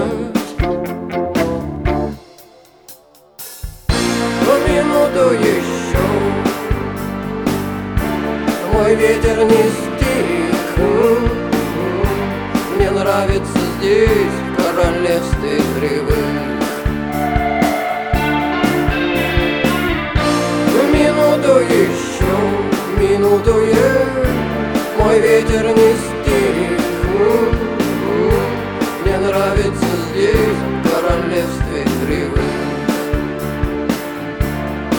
Жанр: Рок / Русские
# Rock